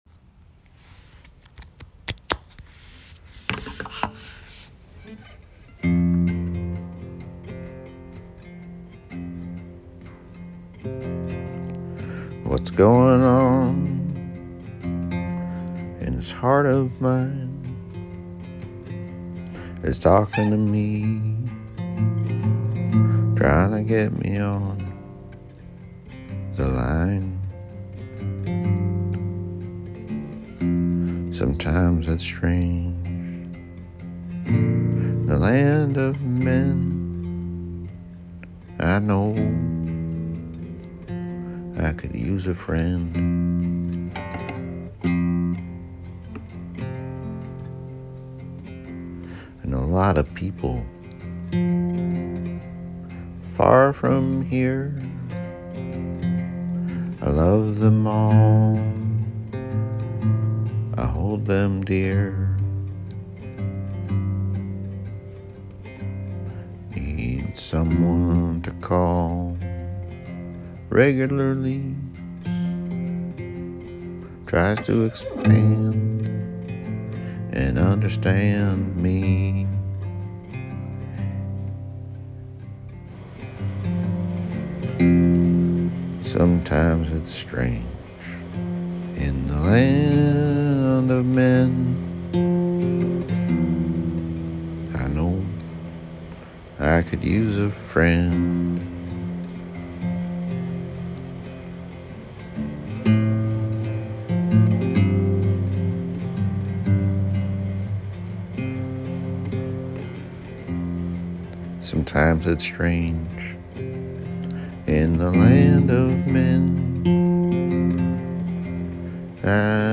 It has a meditative, voice in your head feel.
Musically so sparse but that's exactly right.
And the voice is hypnotic.